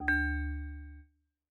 steeltonguedrum_d1.ogg